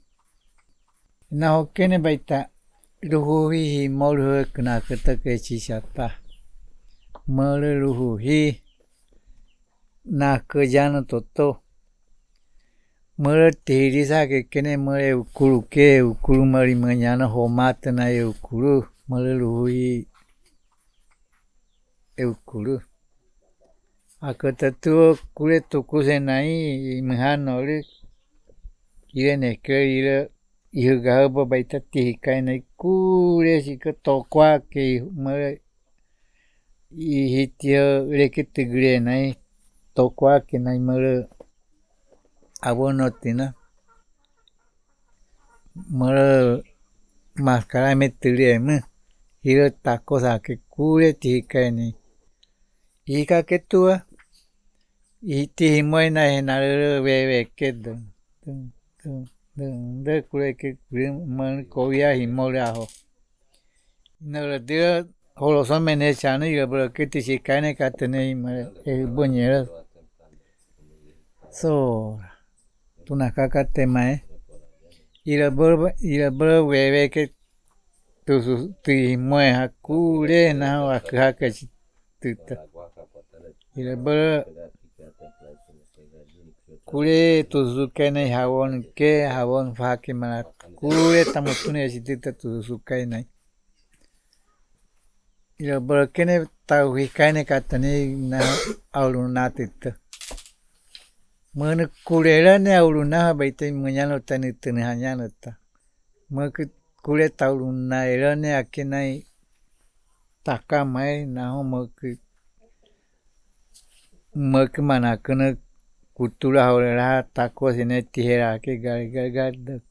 Puerto Nare, Guaviare